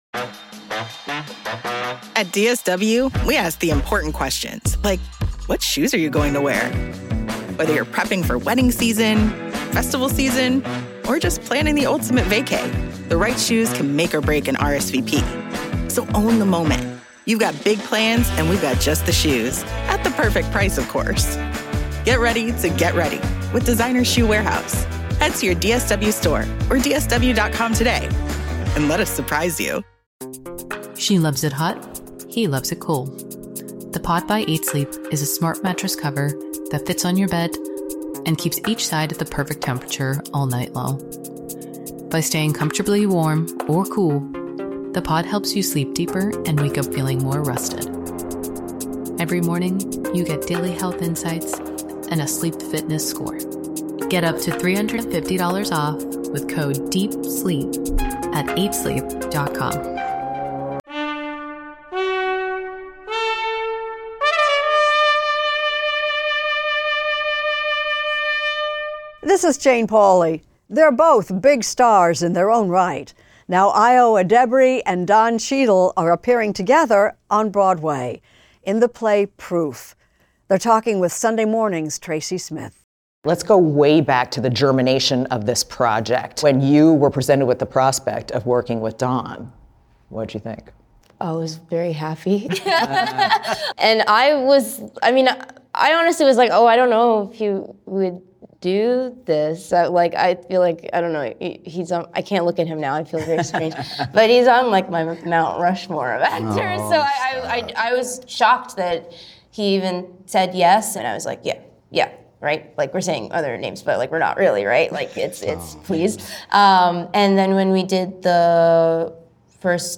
Extended Interview: Ayo Edebiri and Don Cheadle
Actors Ayo Edebiri ("The Bear") and Don Cheadle ("Hotel Rwanda") talk with Tracy Smith about making their Broadway debuts in the revival of "Proof". They also discuss their love of live theater and the early roles that inspired their passion for acting.